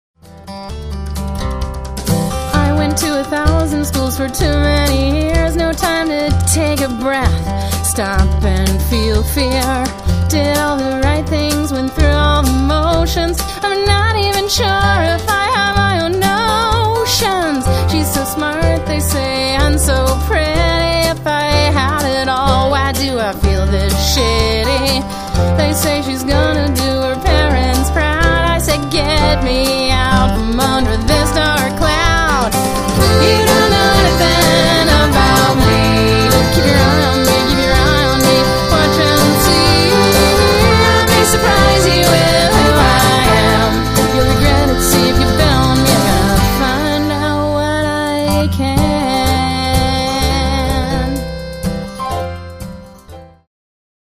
lead vocal, acoustic guitar